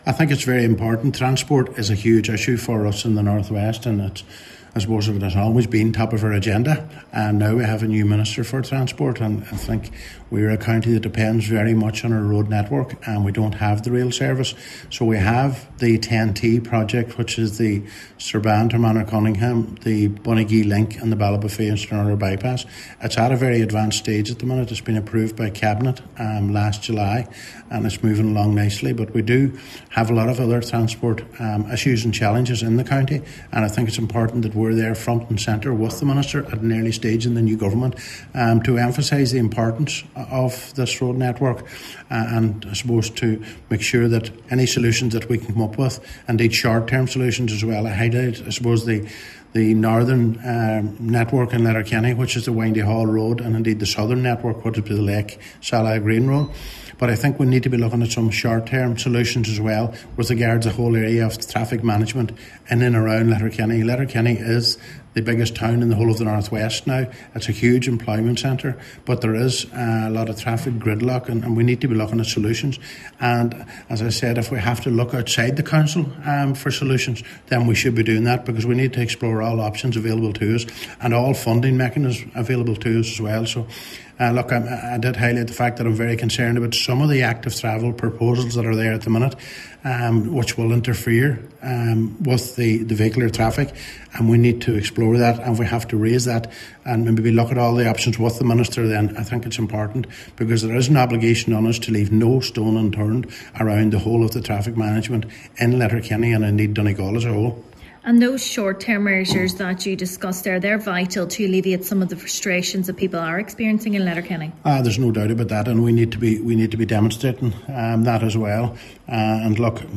Councillor Brogan says there are still ongoing challenges facing Donegal which he believes short term measures could be developed to address them: